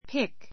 pick 1 中 A1 pík ピ ク 動詞 ❶ （花・果実などを） 摘 つ む , もぐ pick flowers pick flowers 花を摘む pick apples pick apples リンゴをもぐ ❷ （いくつかの中から） 選ぶ （choose, select） She picked a red one from the blouses on the shelf.